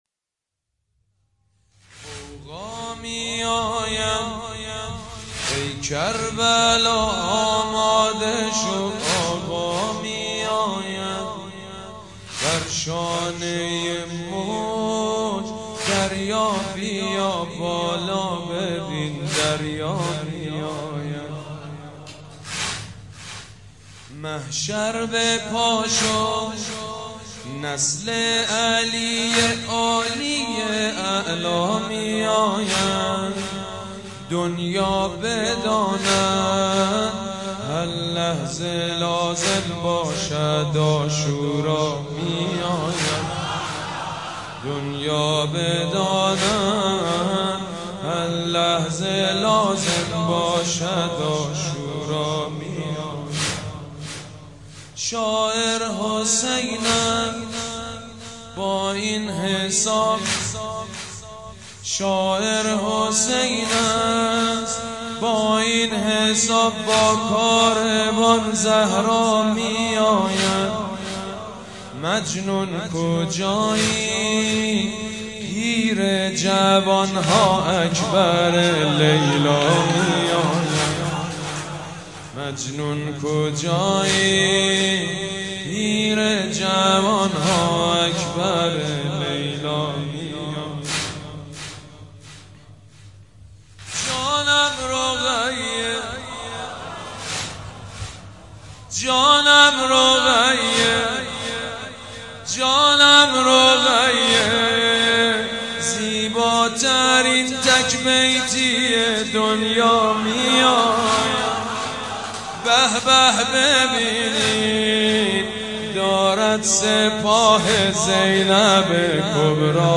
نوحه جديد بنی فاطمه
غوغا می آید ای کربلا آماده شود _ واحد سيد مجيد بنی فاطمه شب دوم محرم 96/06/31